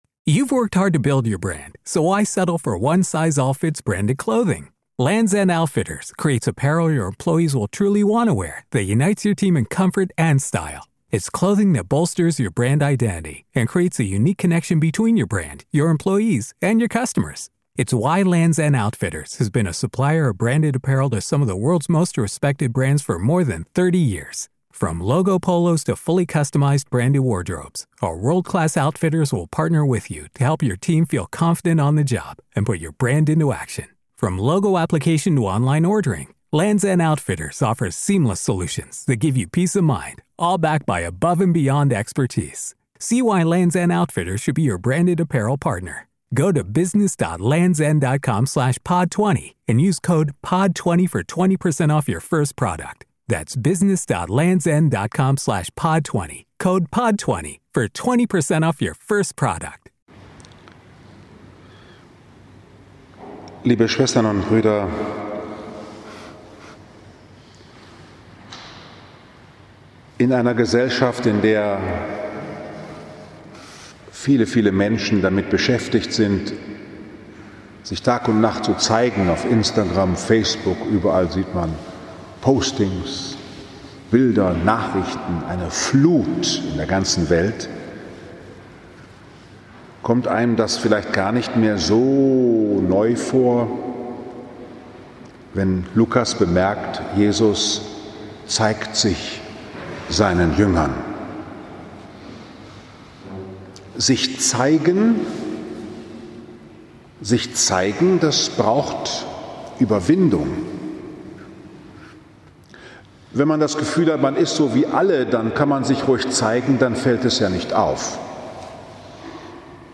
18. April 2021, 11 Uhr Liebfrauenkirche Frankfurt am Main, 3. Sonntag in der Osterzeit B Christliche Osterhoffnung wird von den Gläubigen gezeigt als Hoffnung für alle Welt